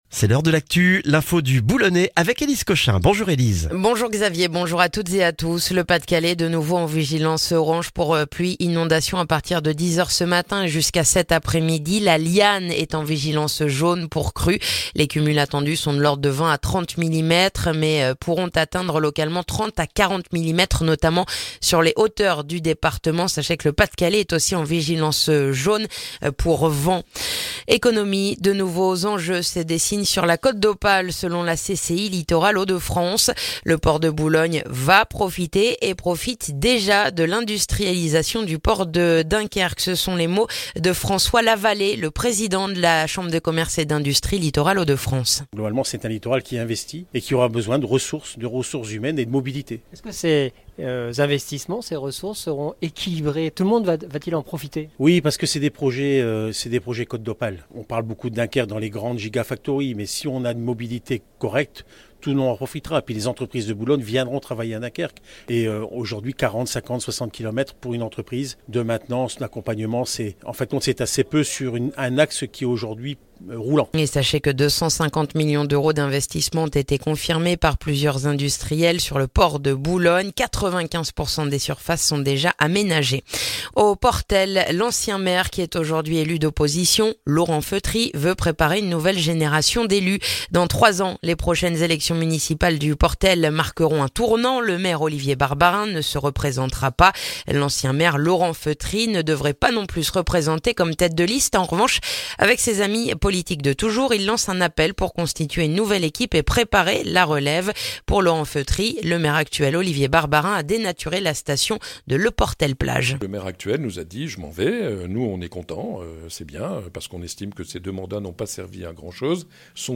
Le journal du mercredi 7 février dans le boulonnais